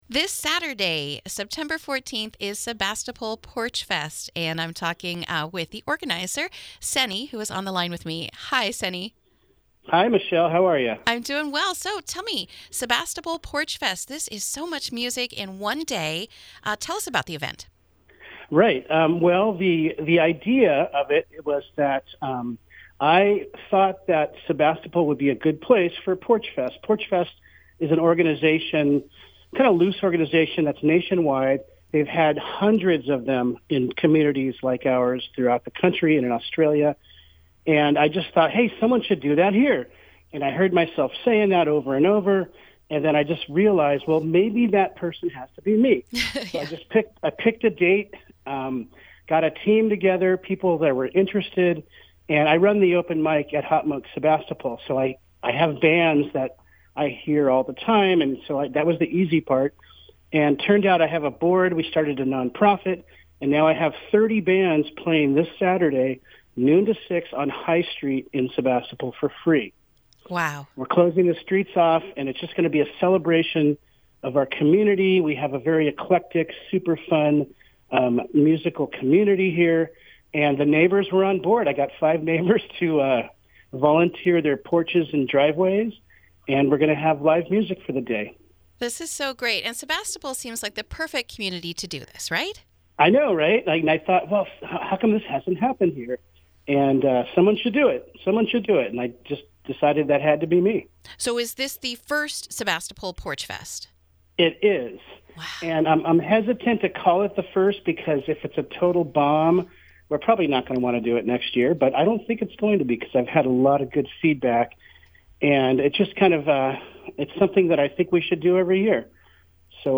INTERVIEW: Sebastopol Porchfest This Saturday, September 14th